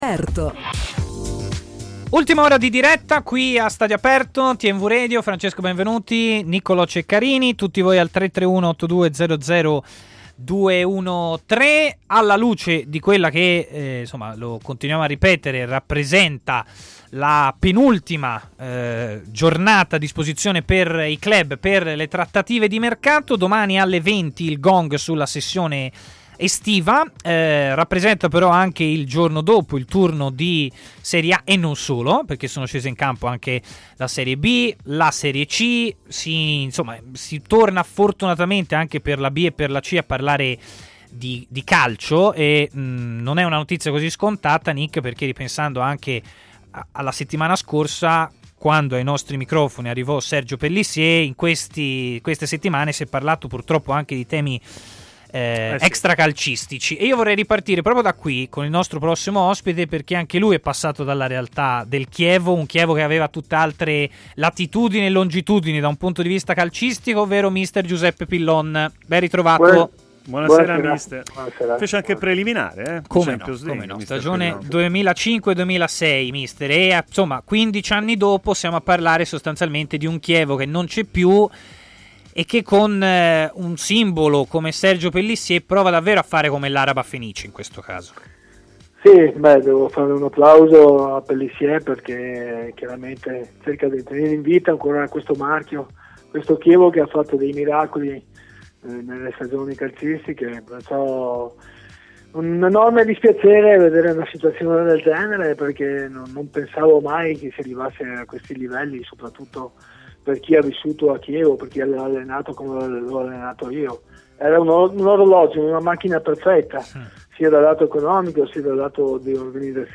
ha parlato in diretta a TMW Radio, nel corso della trasmissione Stadio Aperto